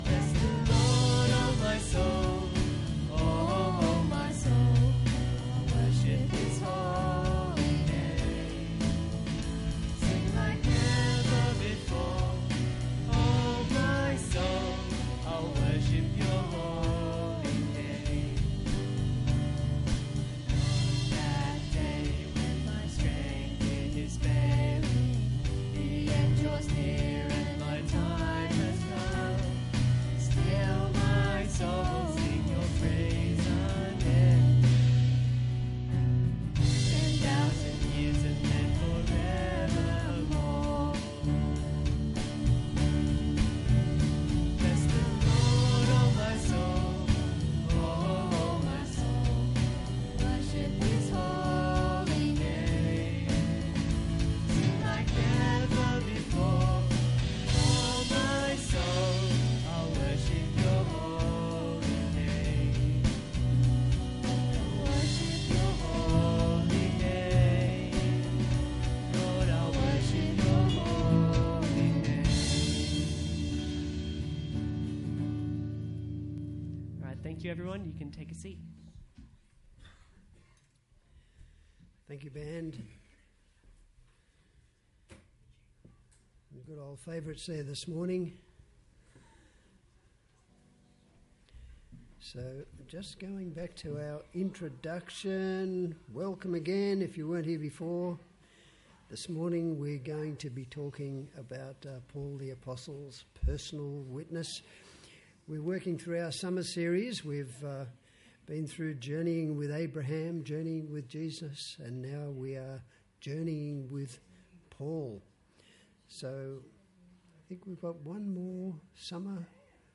Service Type: Sunday Church